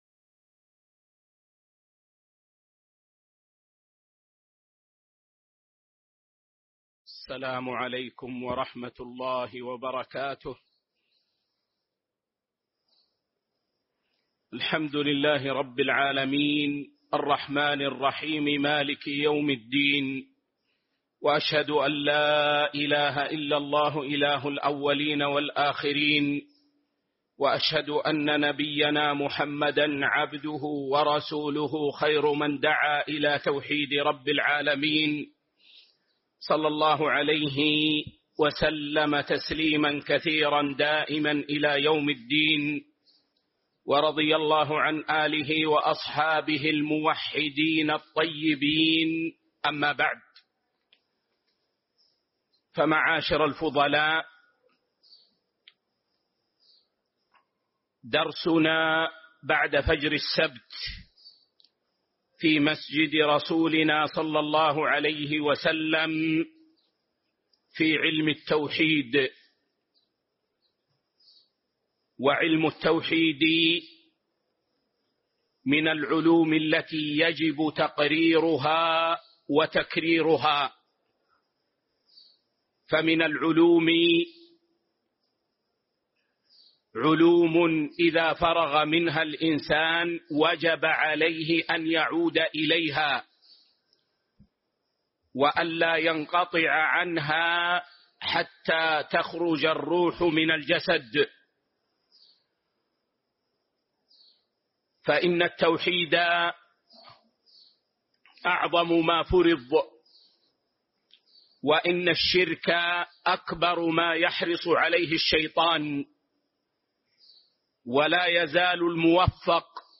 شرح تجريد التوحيد المفيد الدرس 10